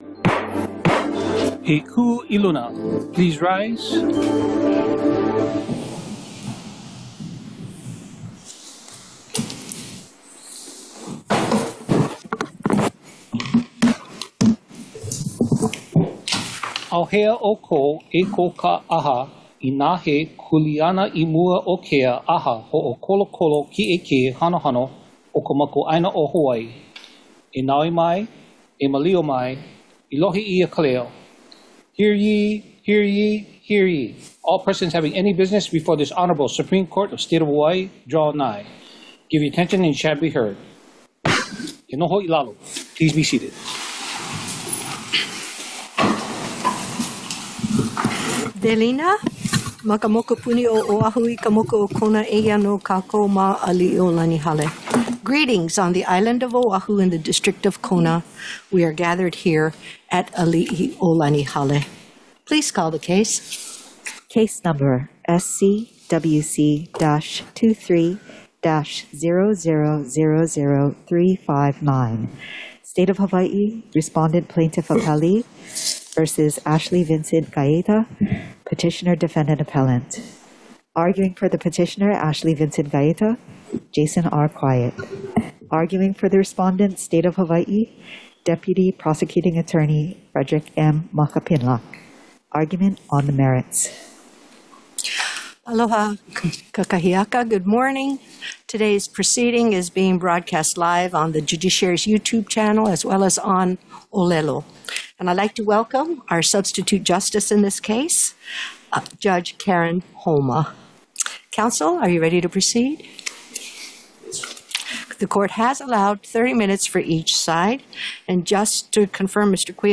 The above-captioned case has been set for oral argument on the merits at: